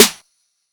Snares
EWTERY_SNR (1).wav